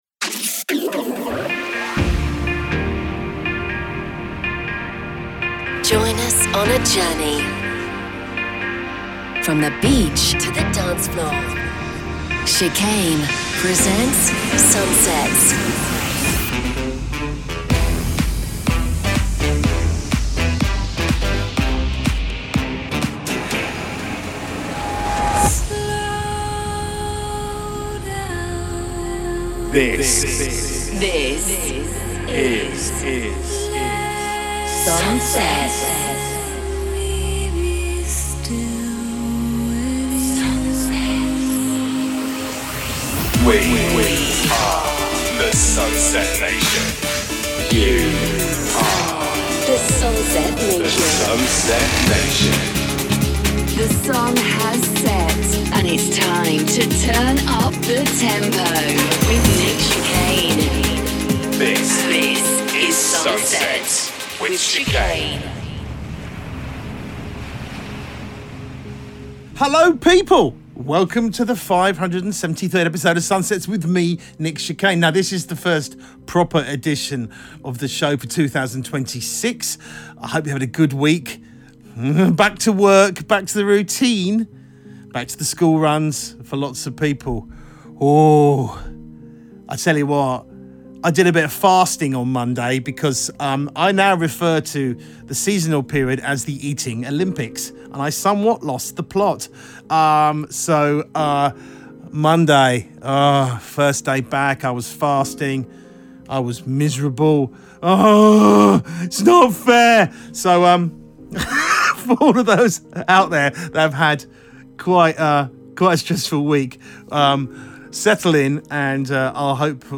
music DJ Mix in MP3 format
Genre: House